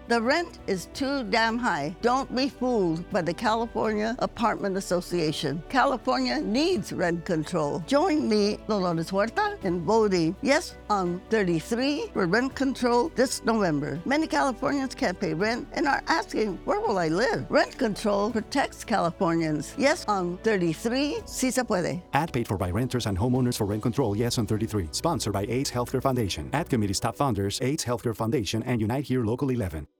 English language radio ad urging California voters to vote Yes on Proposition 33, a November 2024 statewide ballot measure for rent control.